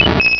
Cri de Mélofée dans Pokémon Rubis et Saphir.